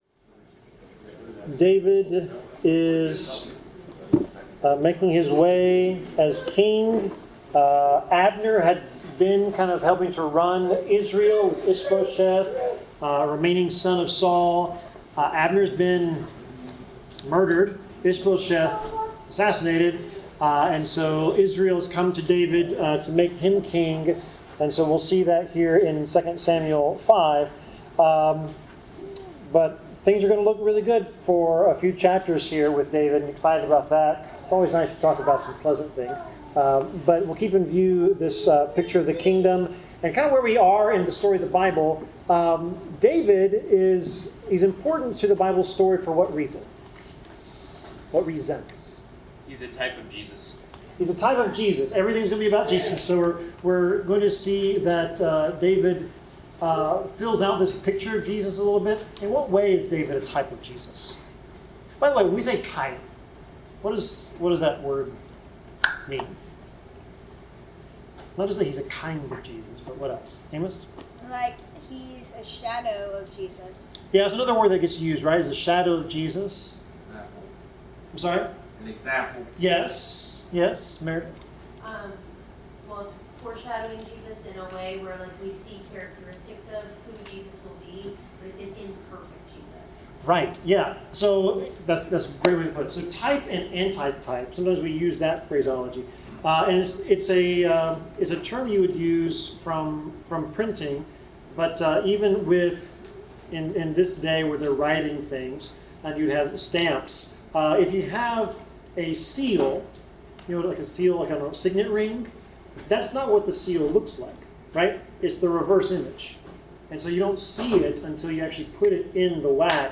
Bible class: 2 Samuel 5-6 (Going to Jerusalem)
Service Type: Bible Class